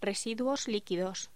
Locución: Residuos líquidos
voz
Sonidos: Voz humana